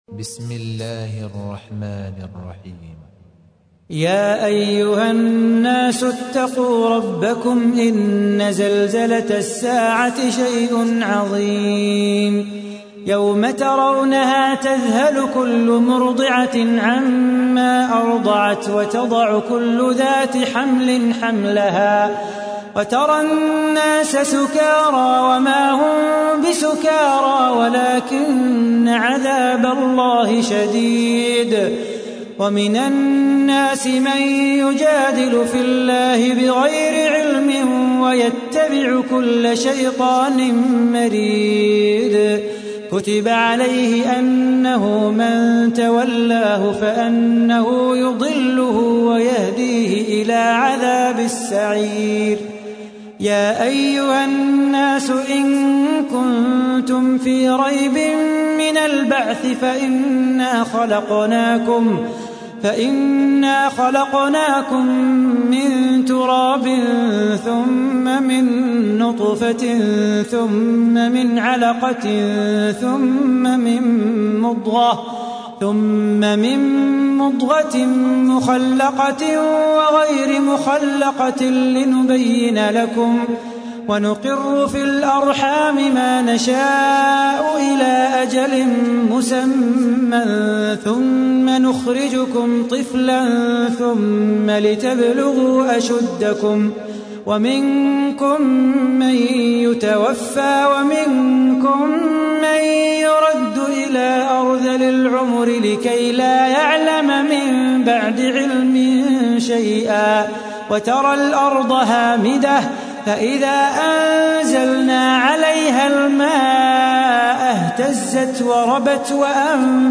تحميل : 22. سورة الحج / القارئ صلاح بو خاطر / القرآن الكريم / موقع يا حسين